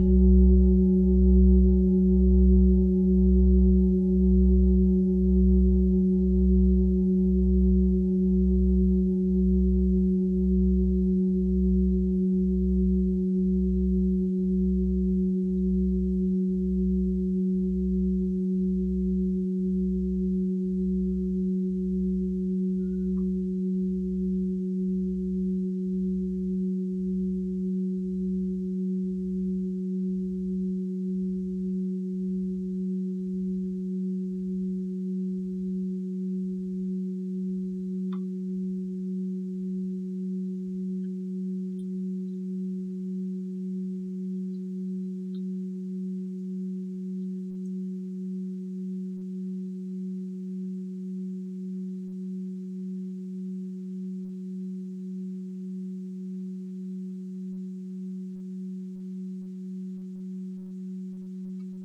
Fuß-Klangschale Nr.4
Sie ist neu und wurde gezielt nach altem 7-Metalle-Rezept von Hand gezogen und gehämmert.
(Ermittelt mit dem Gummischlegel)
fuss-klangschale-4.wav